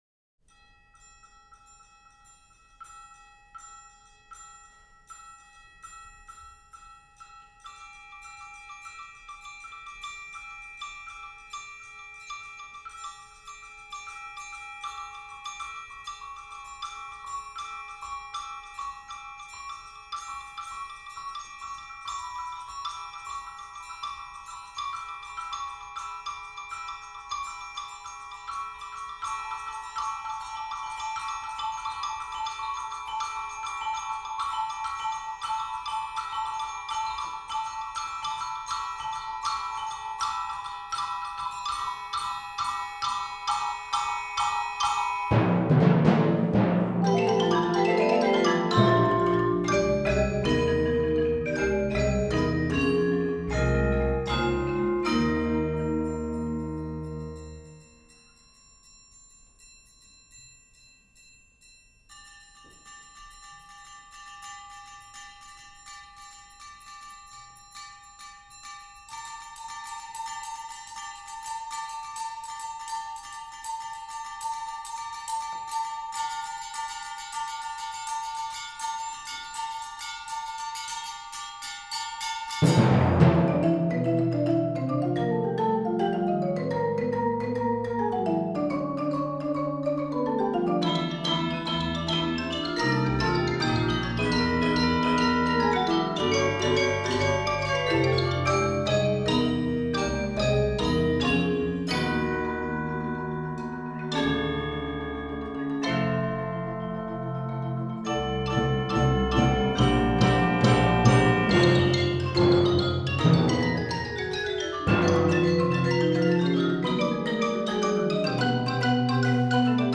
Genre: Percussion Ensemble
# of Players: 12
Bells/Crotales
Xylophone
Chimes
Vibraphone 1
Marimba 3 (5-octave)
Percussion 3 (triangle, 2 tom-toms, claves, hi-hat)
Timpani
Bass Guitar (optional)